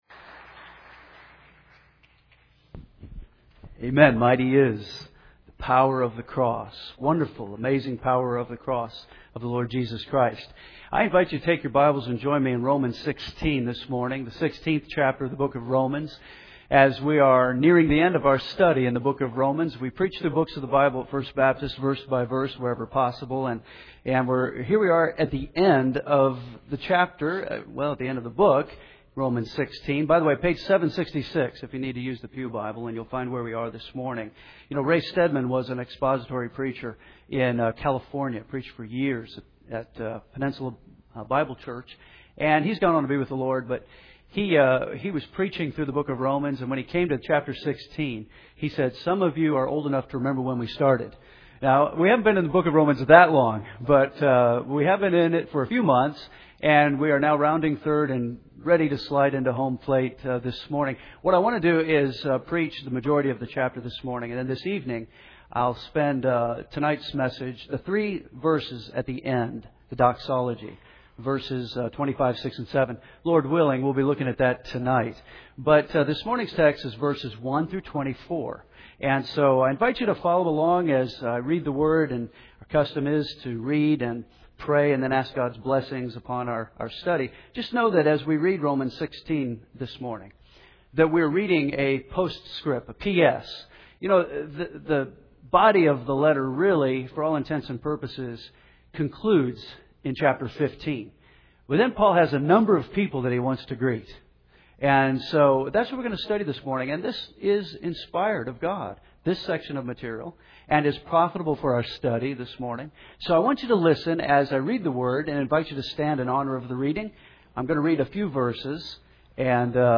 We preach through books of the Bible here at First Baptist, expository preaching, verse-by-verse wherever possible.